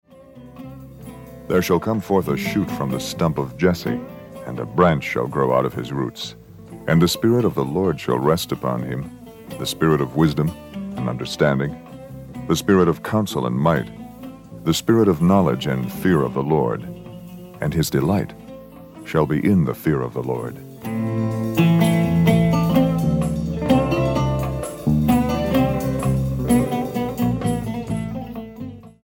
STYLE: Jesus Music
baritone speaking voice
fuzz guitars, groovy sounds and a sprinkling of avant garde